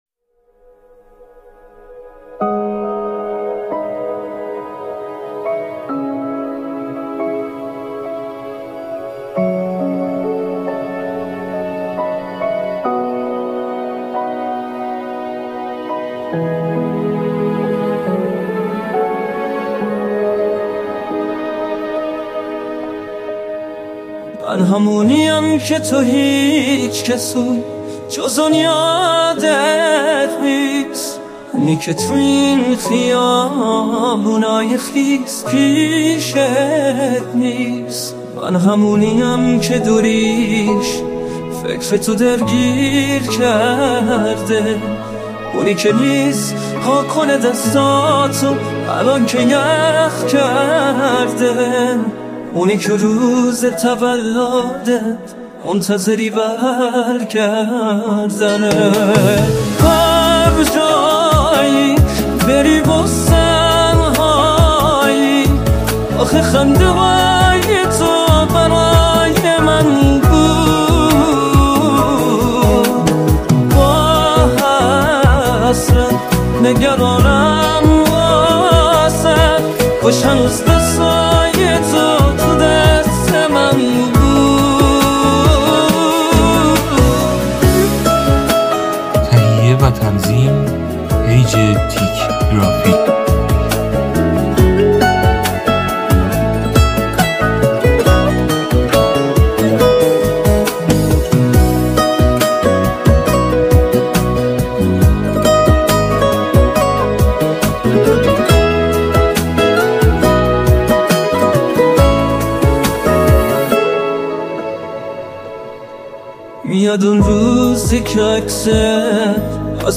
دانلود آهنگ پاپ ایرانی